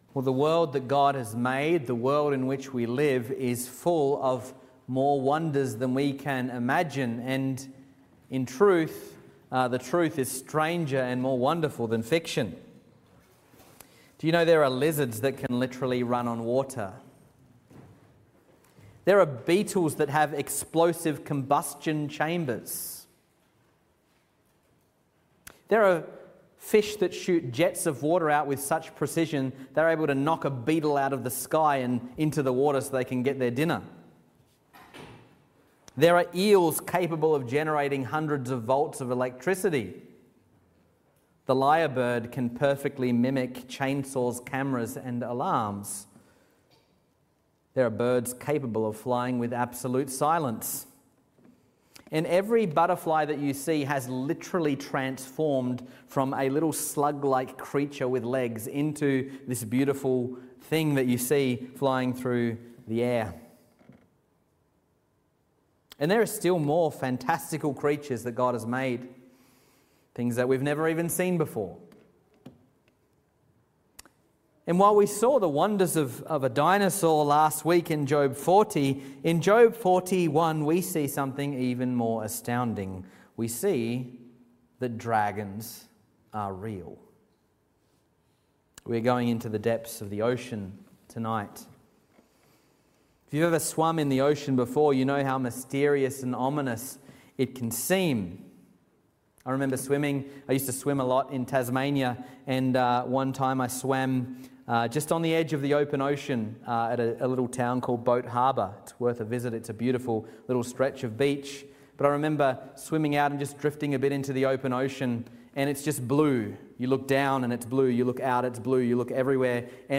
Sermons | Reformed Church Of Box Hill